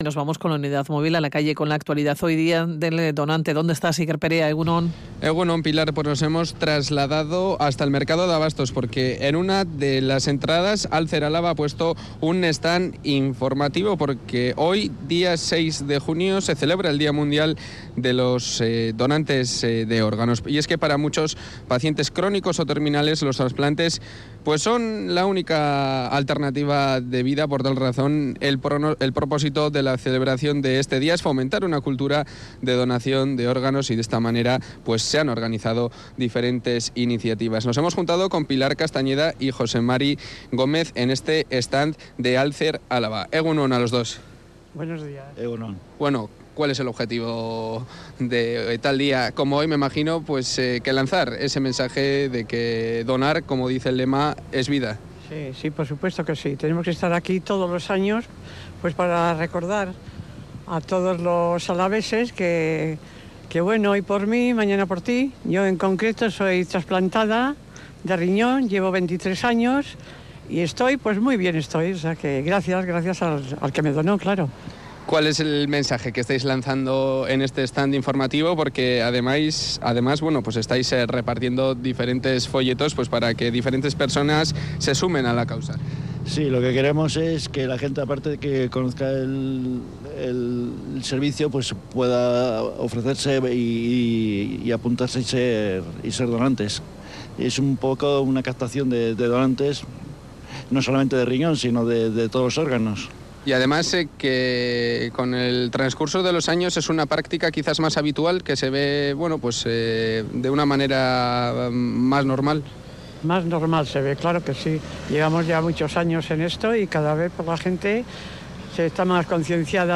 En el Día Mundial del Donante de Órgano hemos charlado con Alcer Álava